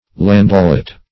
Search Result for " landaulet" : The Collaborative International Dictionary of English v.0.48: Landaulet \Lan`dau*let"\, n. [Cf. F. landaulet, dim, of landau.